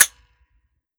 Foley